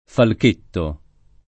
falk%tto] s. m. — piccolo falco: quel profilo di falchetto tra i dolci occhi di colomba [